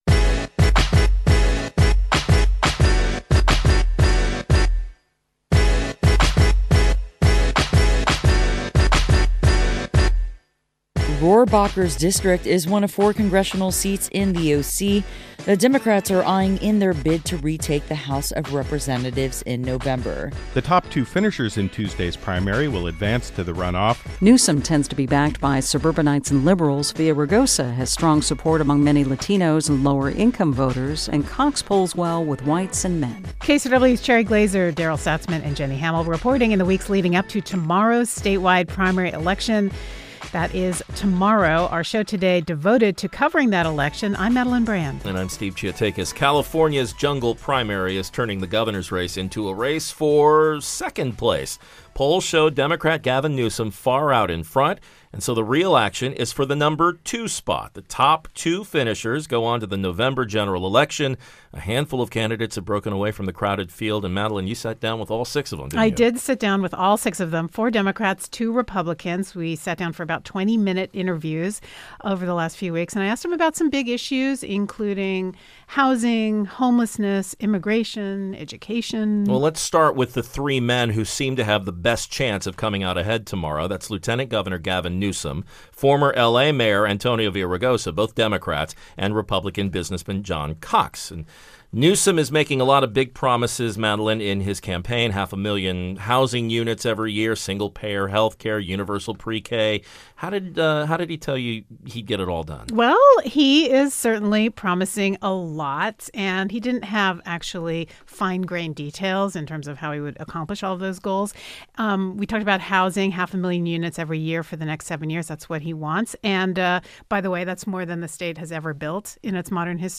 Press Play interviewed all six leading contenders in the governor’s race: Gavin Newsom, Antonio Villaraigosa, John Cox, John Chiang, Delaine Eastin and Travis Allen. We review where they stand on key issues: housing, homelessness, immigration, and education.